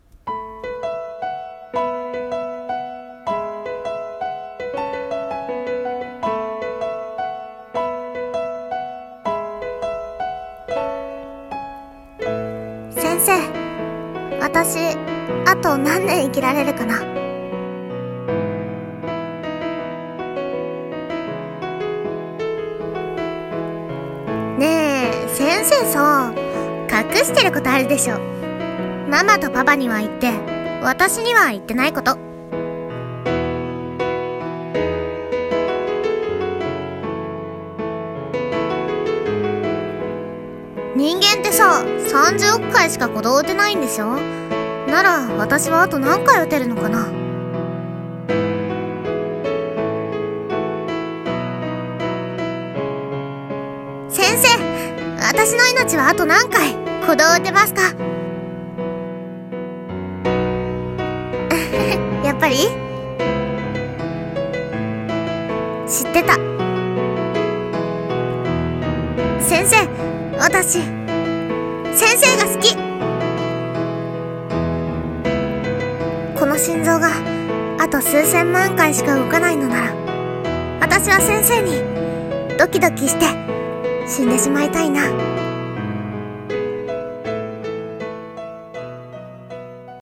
【映画予告風声劇】1800万回の愛を